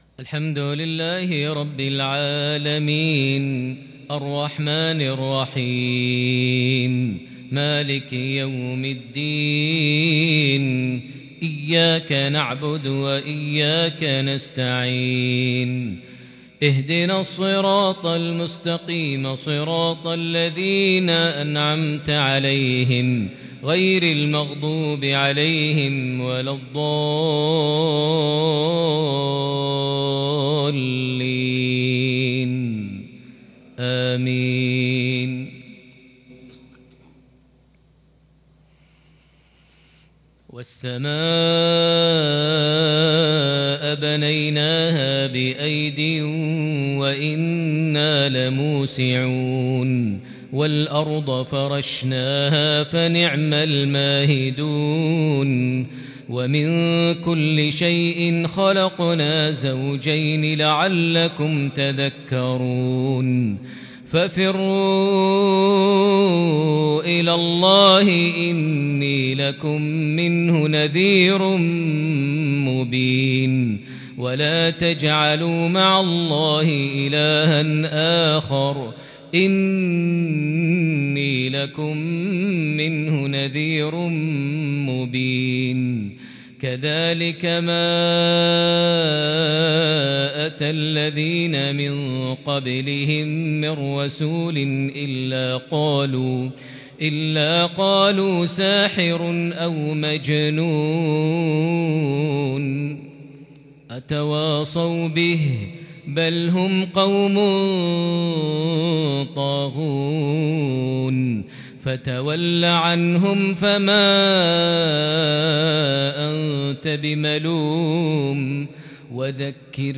مغربية متألقة لخواتيم سورة الذاريات | 25 ربيع الثاني 1442هـ > 1442 هـ > الفروض - تلاوات ماهر المعيقلي